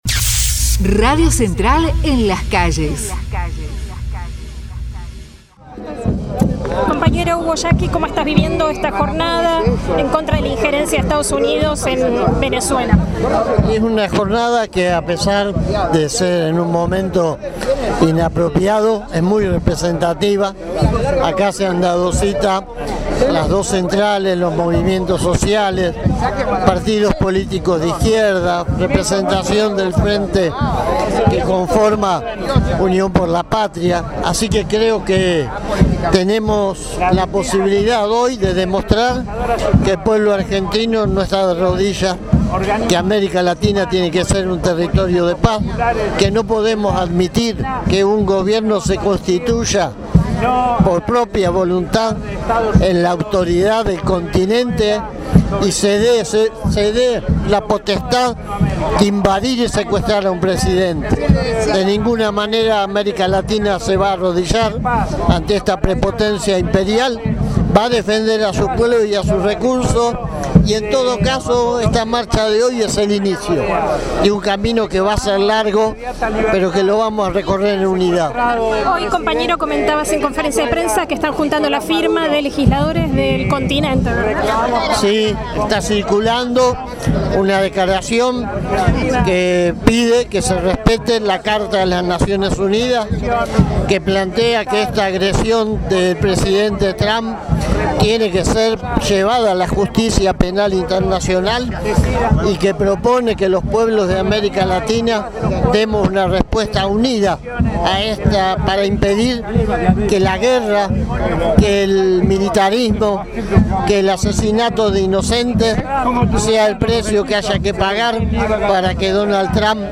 MARCHA CONTRA LA INJERENCIA DE EE.UU. EN VENEZUELA: Testimonios CTA